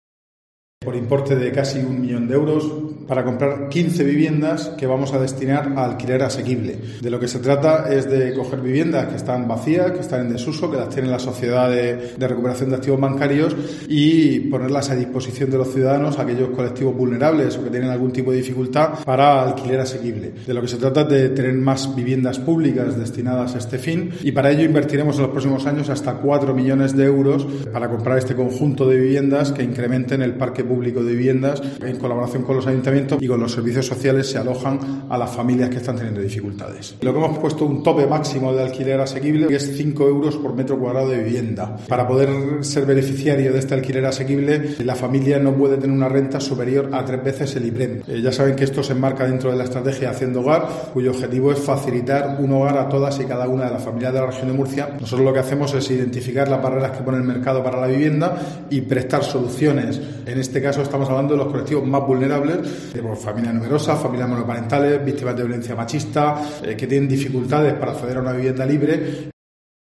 José Ramon Díez de Revenga, consejero de Fomento e Infraestructuras